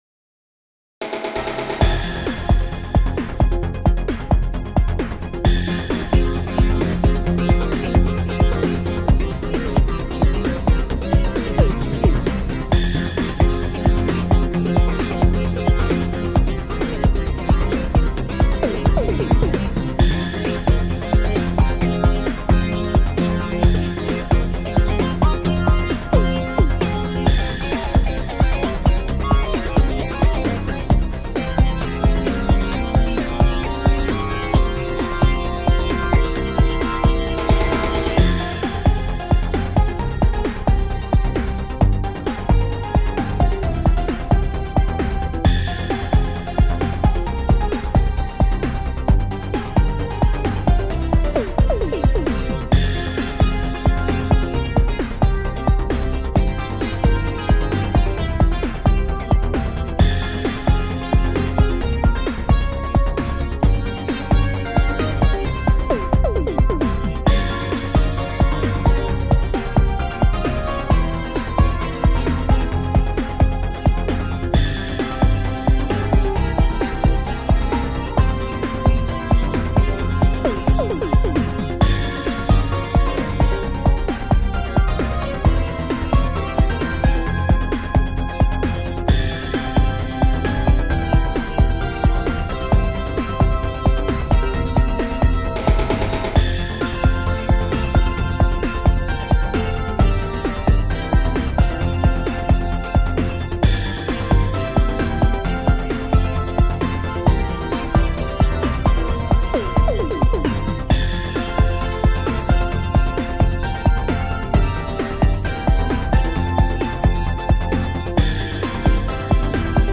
BGM
エレクトロニカロング暗い